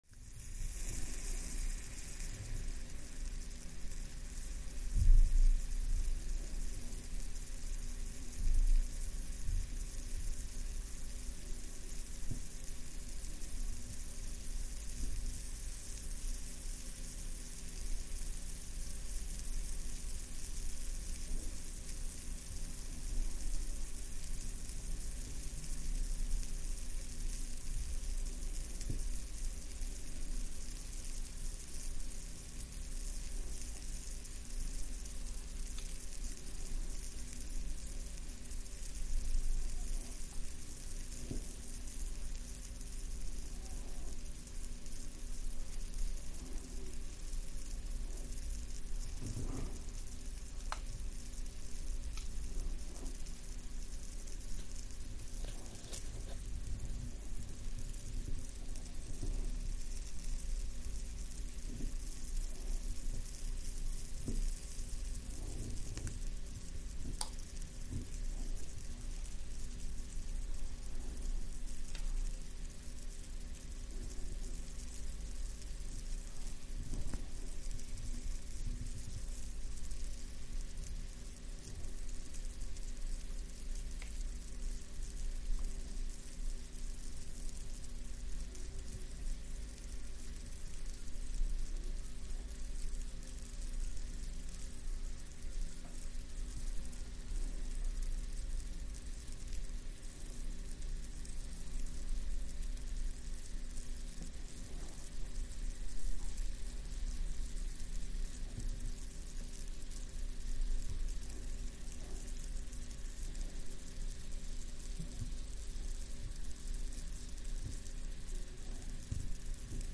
На этой странице собраны уникальные звуки песочных часов — от мягкого пересыпания песка до четких щелчков при перевороте.
Звук песка в песочных часах на 2 минуты